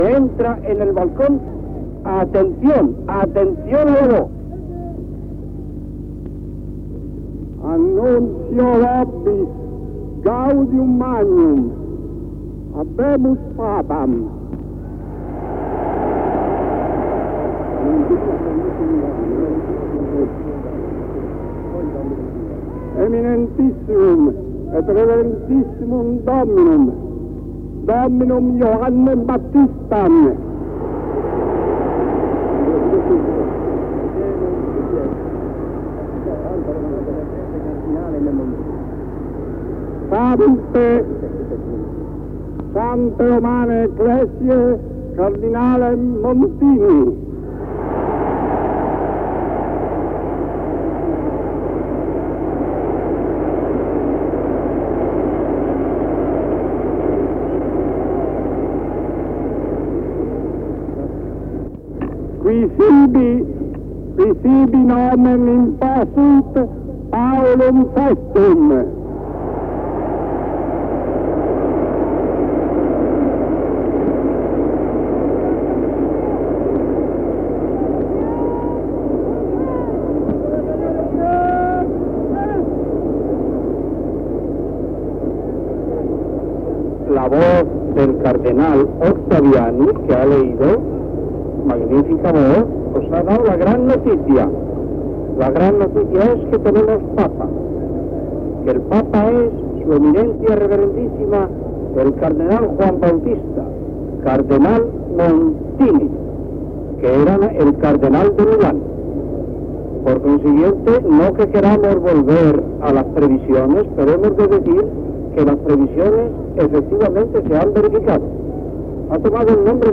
Transmissió, des de la Ciutat del Vaticà, de la prroclamació del cardenal Giovanni Battista Montini com a Sant Pare Pau VI. Biografia seva i primeres paraules des del balcó de la Bsílica de Sant Pere, dades sobre la feina feta pel Cardenal Montini.